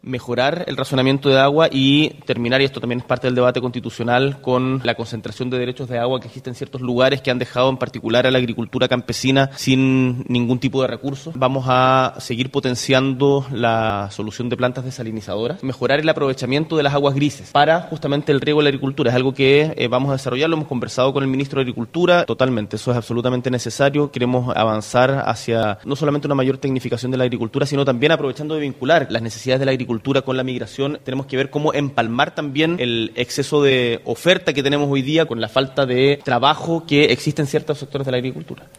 Pasadas las ocho de la mañana de este lunes comenzó la entrevista al presidente de la república, Gabriel Boric, por parte de la Asociación de Radiodifusores de Chile, ARCHI, donde se abordaron diversas temáticas en la casi media hora que duró el diálogo.